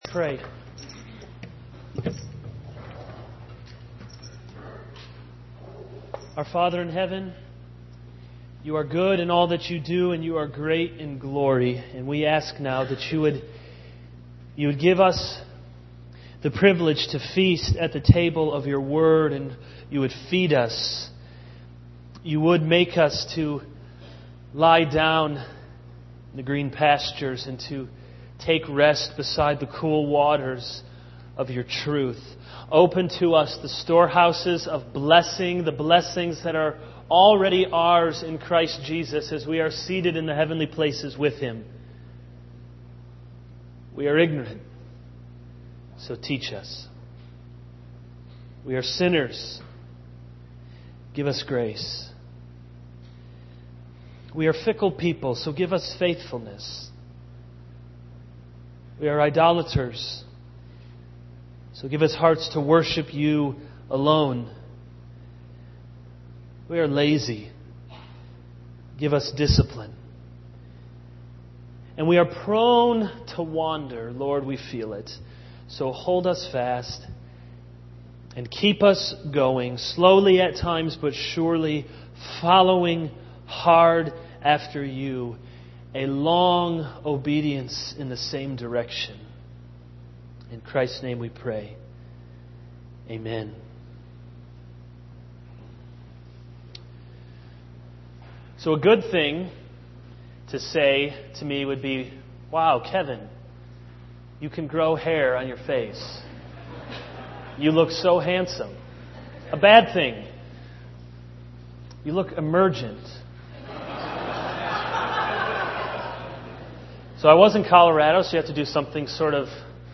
This is a sermon on 2 Corinthians 6:1-10.